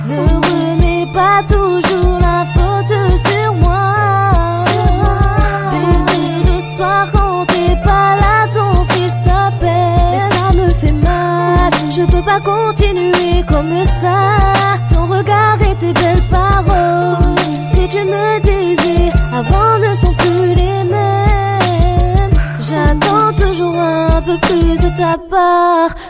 • Качество: 128, Stereo
красивый женский голос
французский рэп
французский рэпчик